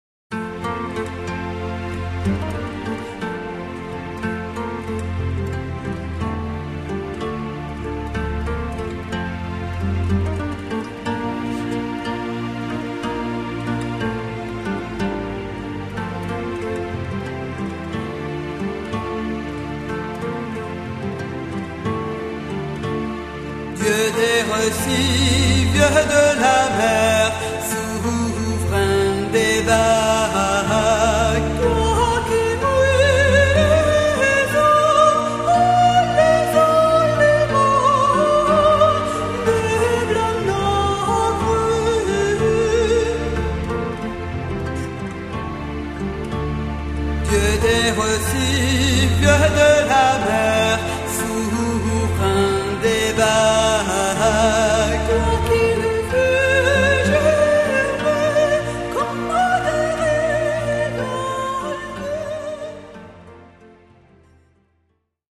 Forlane